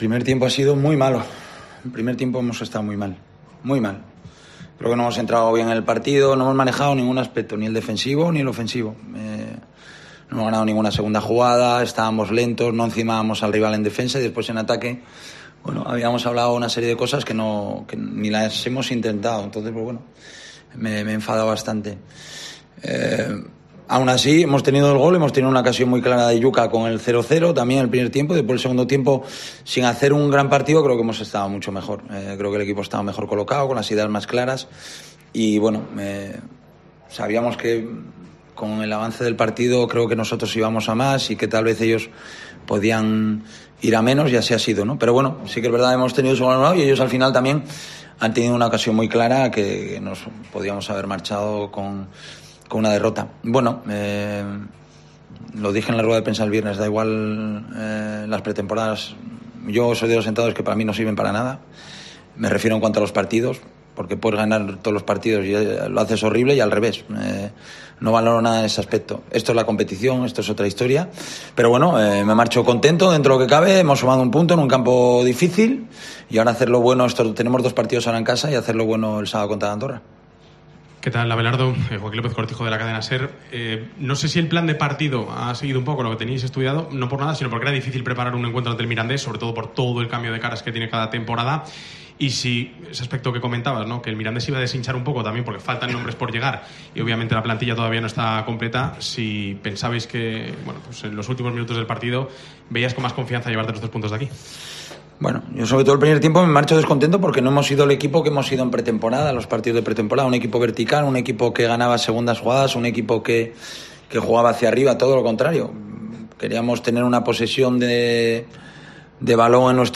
Rueda de prensa Abelardo (post Mirandés)